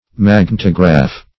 Magnetograph \Mag*net"o*graph\, n. [Magneto- + -graph.]